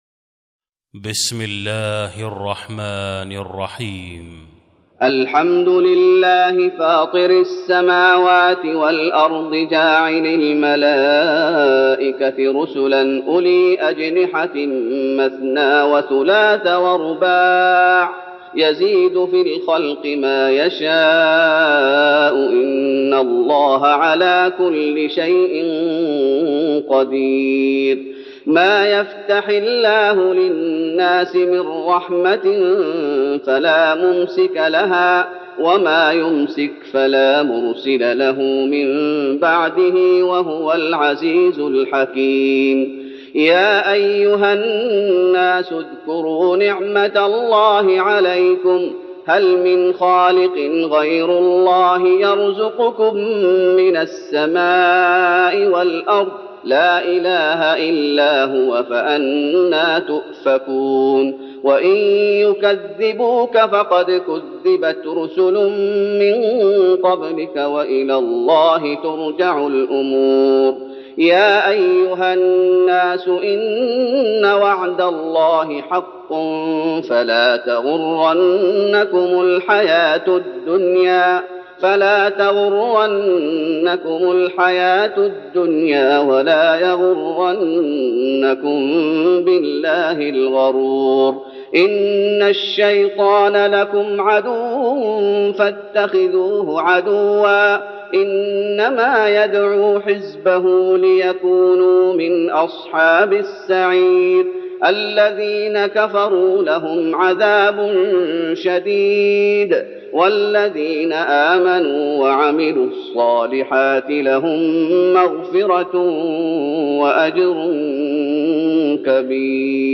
تراويح رمضان 1412هـ من سورة فاطر Taraweeh Ramadan 1412H from Surah Faatir > تراويح الشيخ محمد أيوب بالنبوي 1412 🕌 > التراويح - تلاوات الحرمين